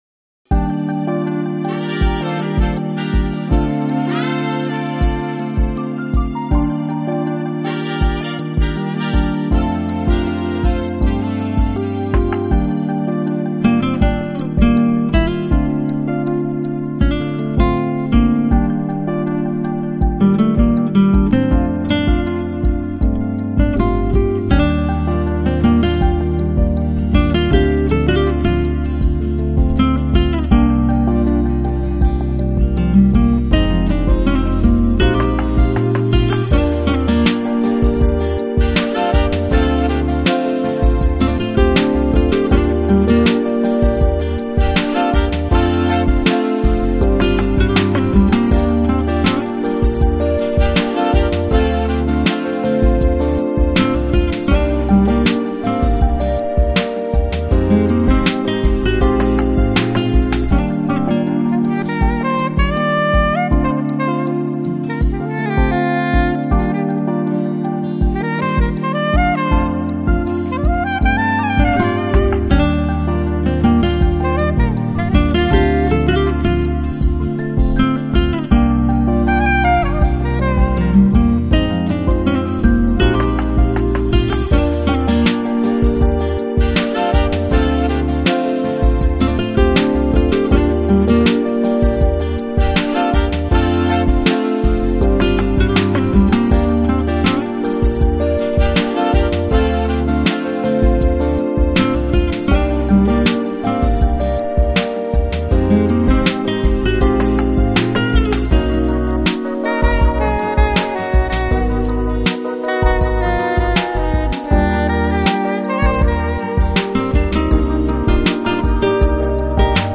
Жанр: Lounge, Chill Out, Smooth Jazz, Easy Listening